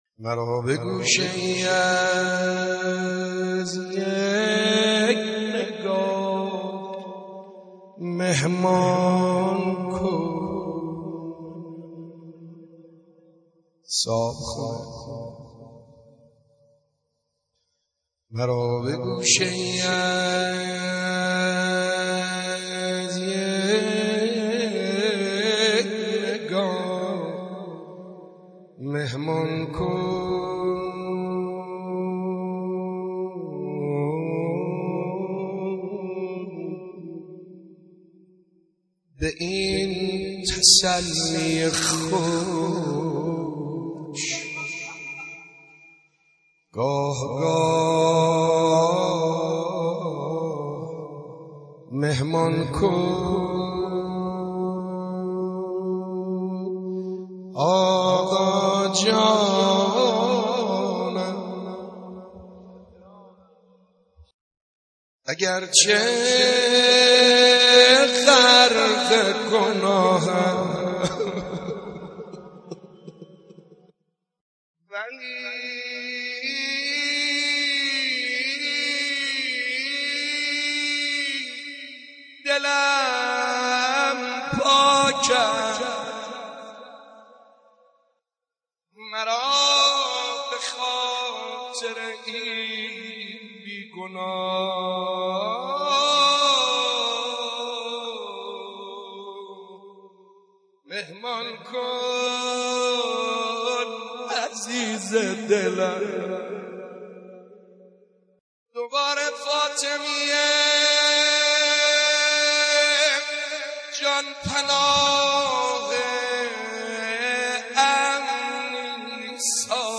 زبانحال حضرت زهرا(س) به امیرالمومنین علی(ع) با مداحی «سعید حدادیان» (3:51)